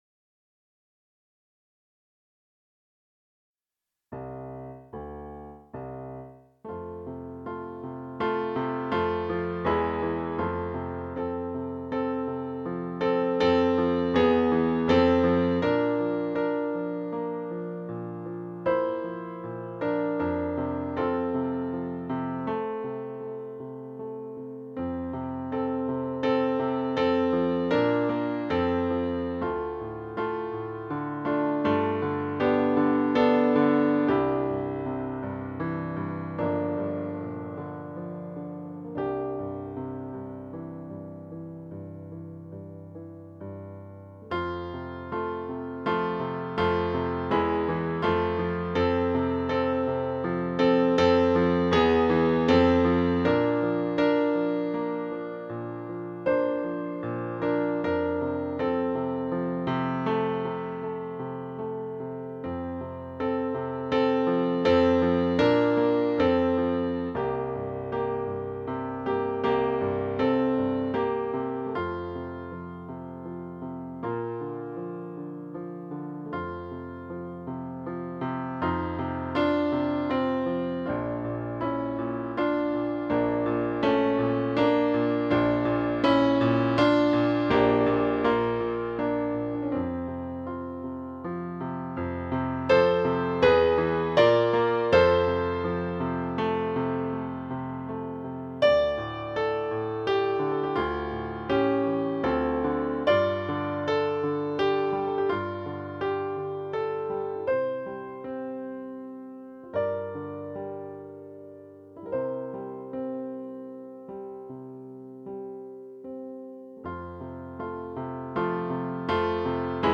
vánoční koledu